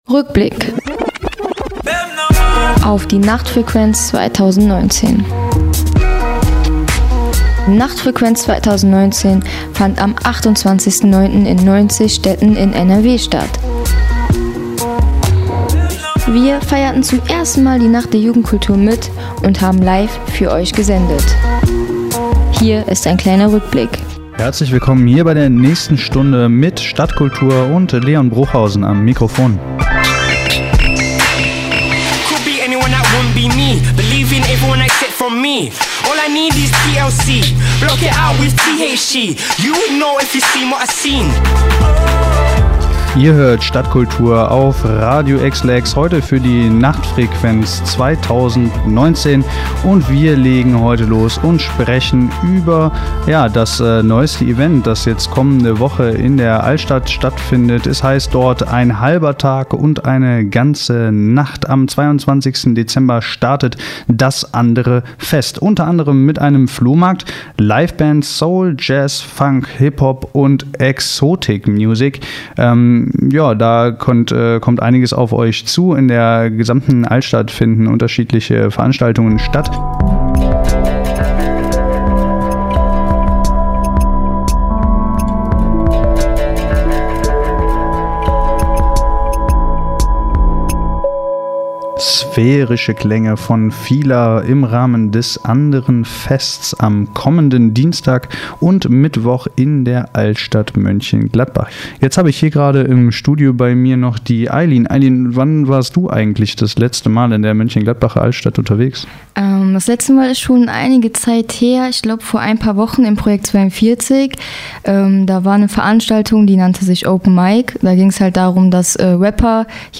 Für Euch haben wir jetzt die besten Ausschnitte unserer Live-Sendung während dieser „langen Nacht der Jugendkultur“ herausgesucht.